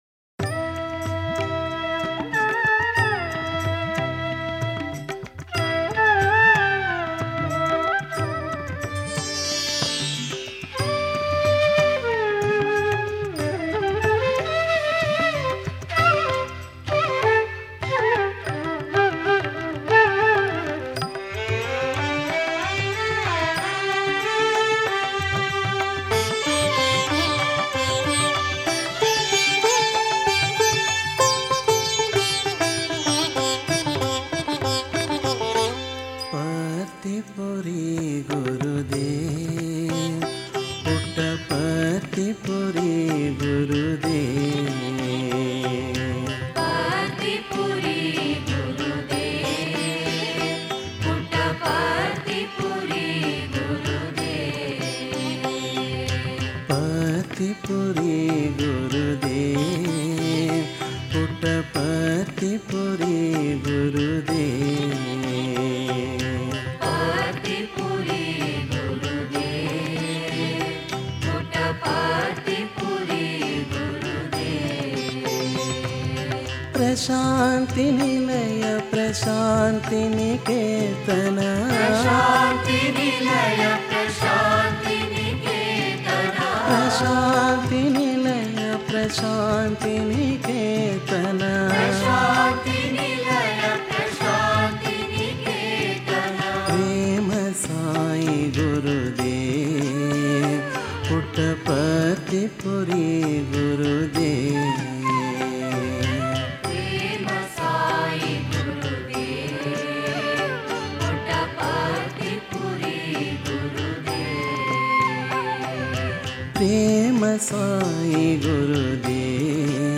Author adminPosted on Categories Guru Bhajans